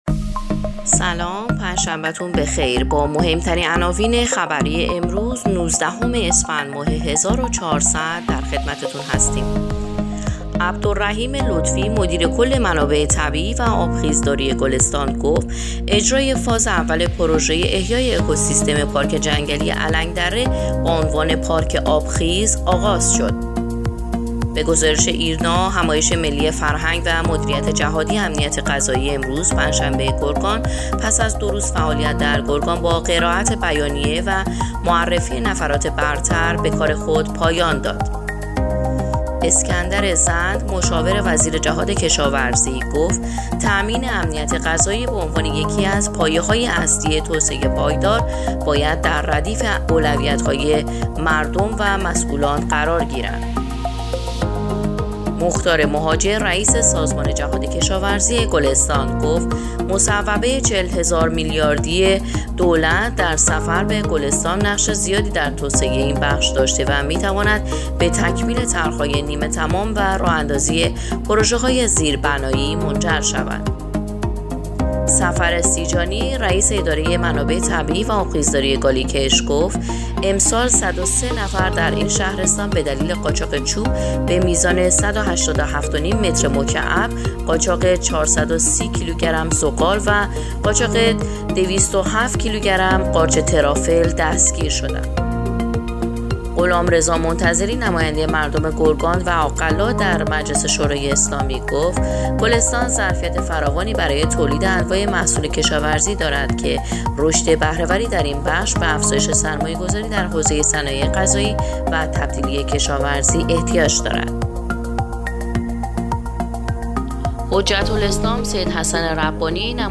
پادکست/ اخبار شبانگاهی نوزدهم اسفندماه ایرنا گلستان